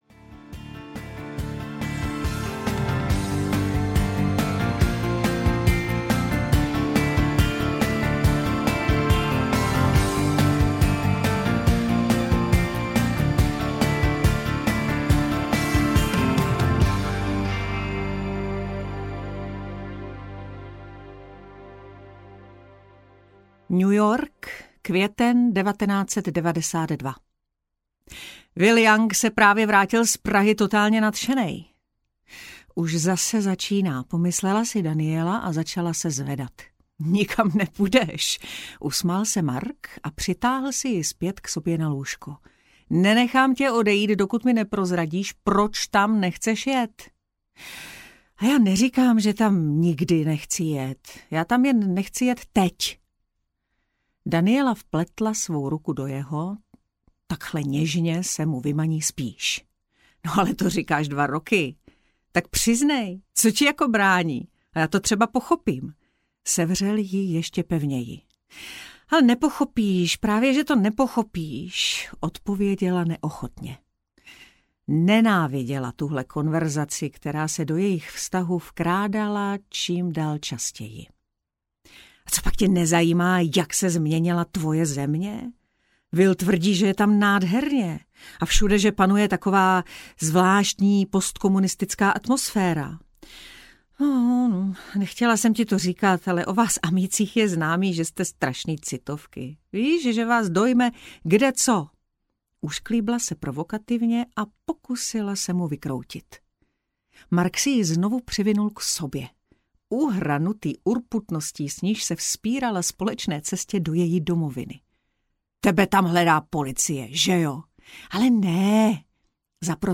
Ten sen audiokniha
Ukázka z knihy